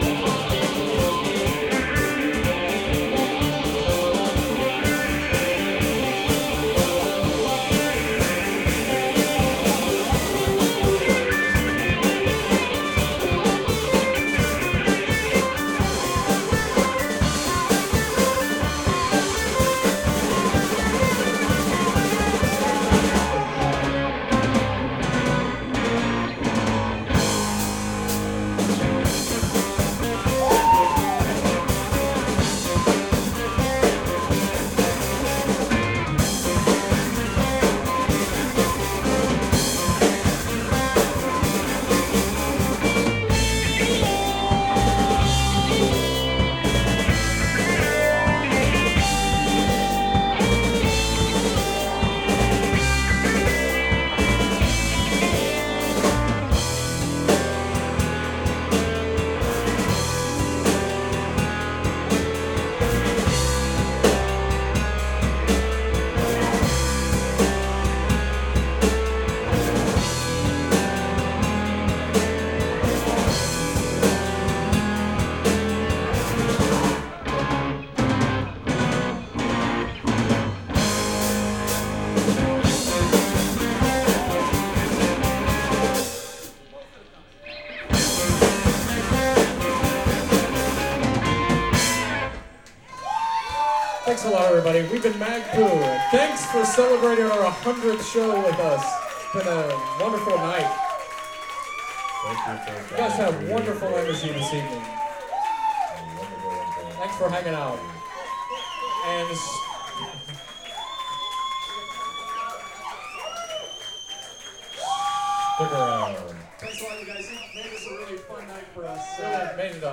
guitar
bass
keyboards
malletKAT
drums